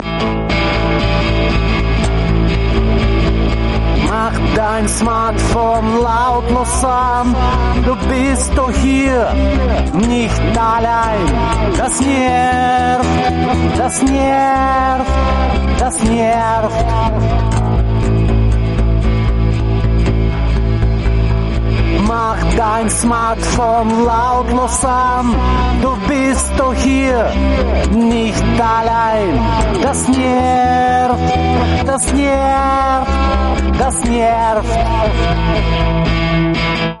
Das ist ein Jingle für Fahrgäste in öffentlichen Verkehrsmittel, die von Menschen genervt sind, die ihr Smartphone laut scrollen.